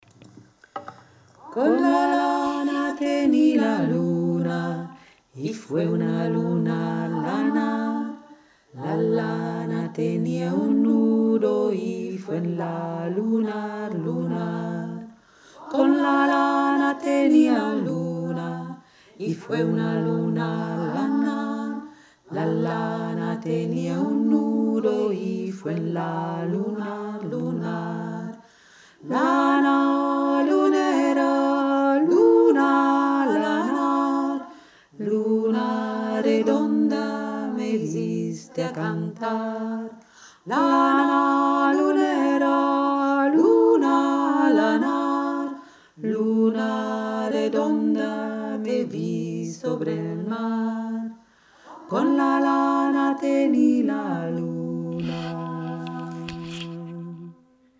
a due voci voce bassa